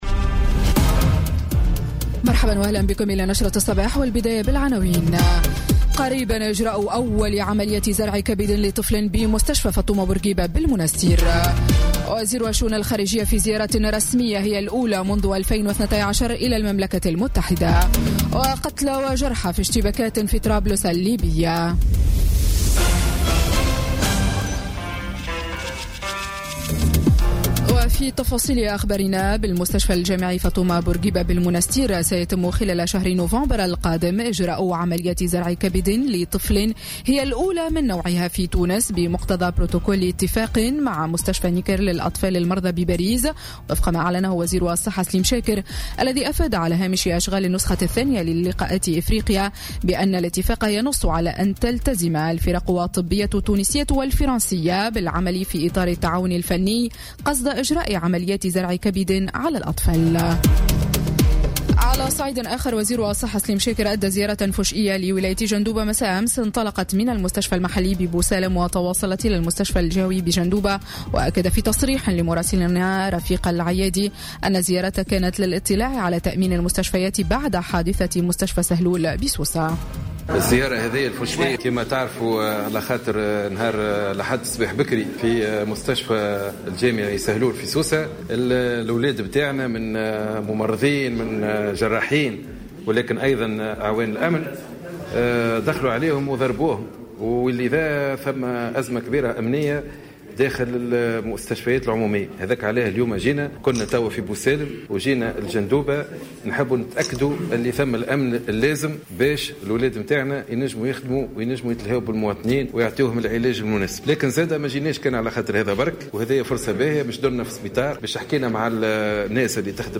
نشرة أخبار السابعة صباحا ليوم السبت 7 أكتوبر 2017